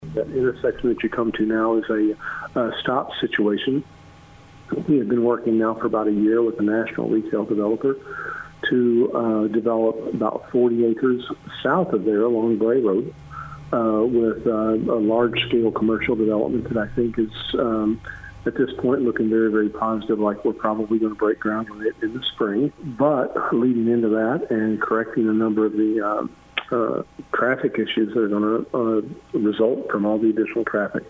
City Administrator Greg Beavers says the city wants feedback from residents about the project.